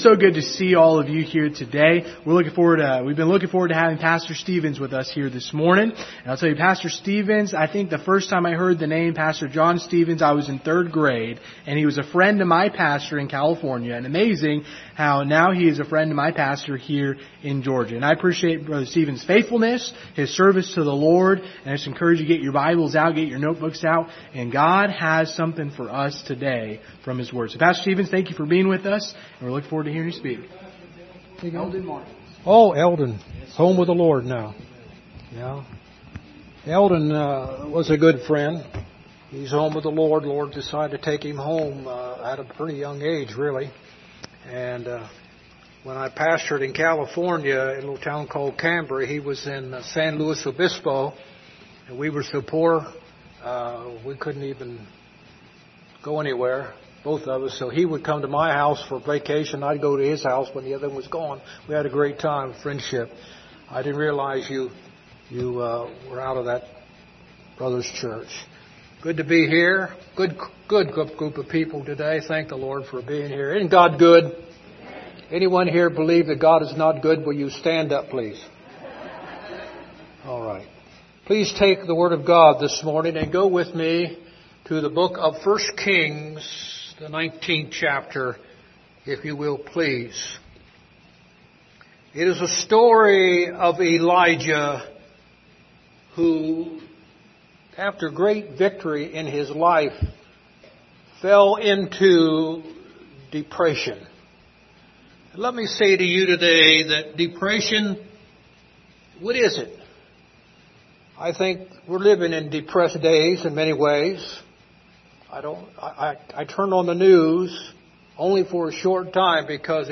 Passage: I Kings 19 Service Type: Sunday Morning Topics